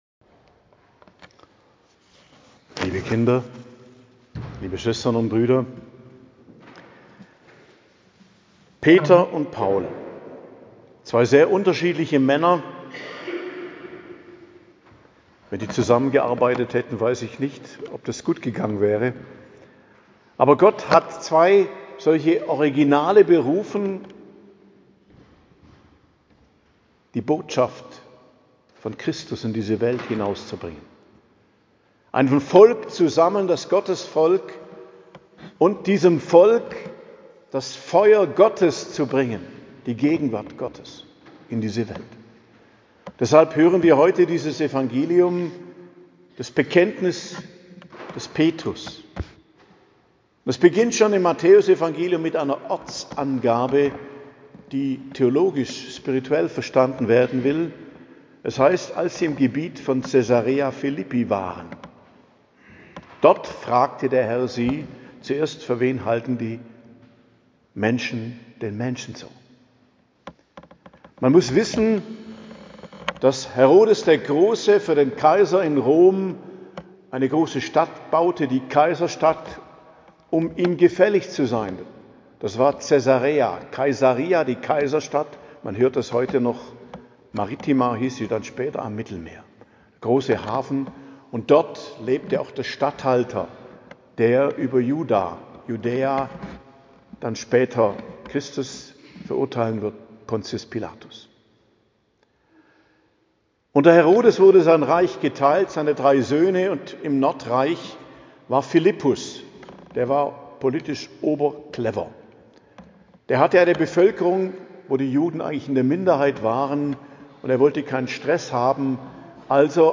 Predigt zum Hochfest Hl.